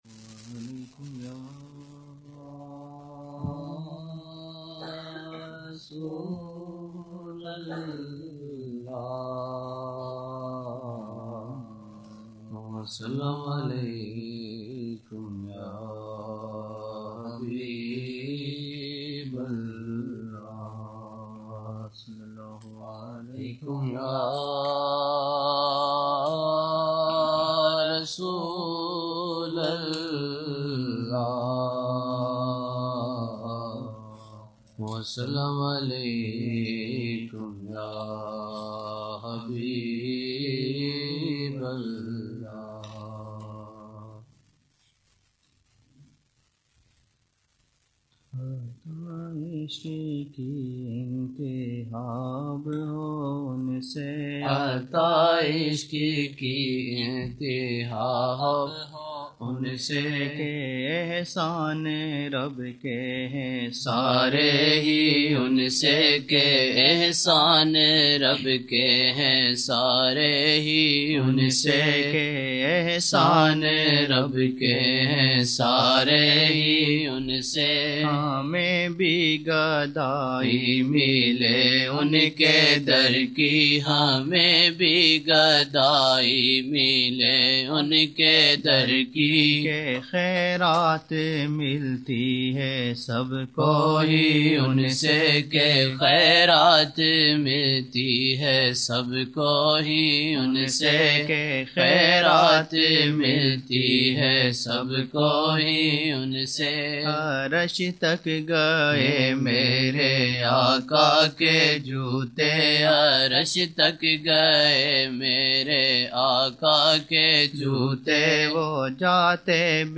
19 November 1999 - Jumma mehfil (11 Shaban 1420)